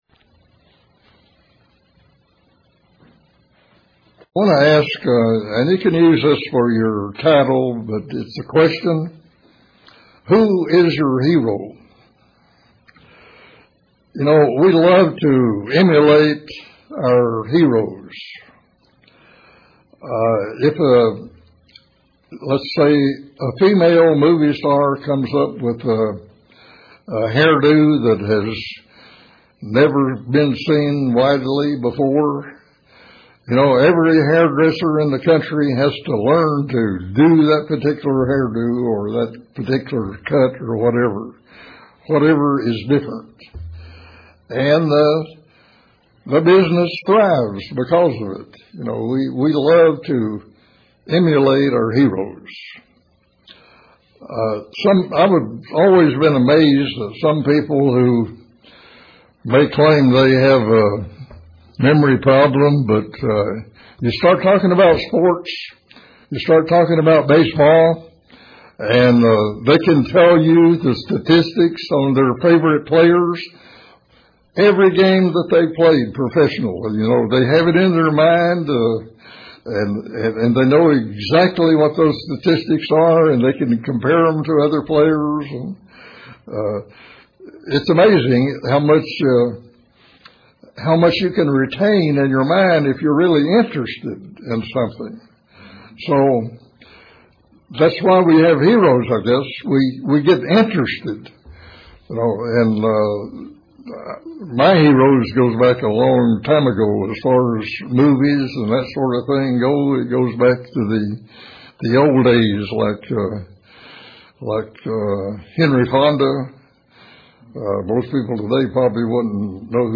Find out in this sermon....Find out just who our hero should be and why....